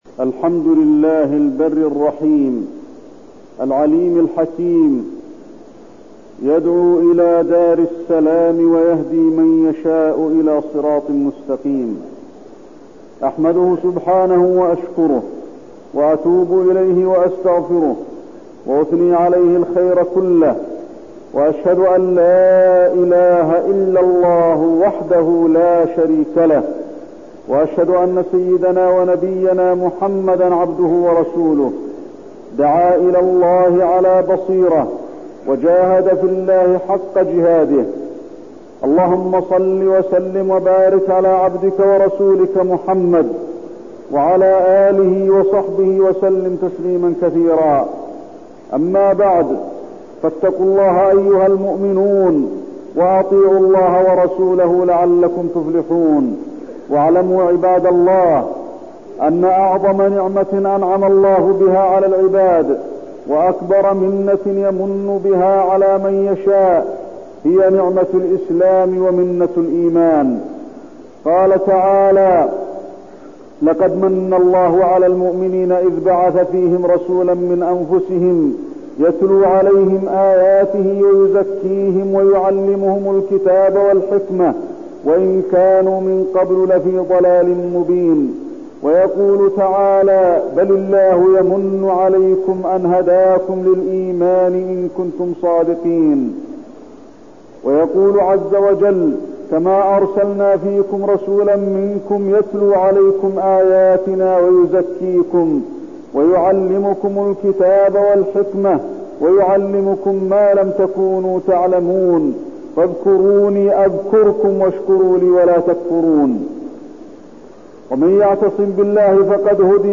تاريخ النشر ٢٥ ذو الحجة ١٤٠٩ هـ المكان: المسجد النبوي الشيخ: فضيلة الشيخ د. علي بن عبدالرحمن الحذيفي فضيلة الشيخ د. علي بن عبدالرحمن الحذيفي الدعوة إلى الله تعالى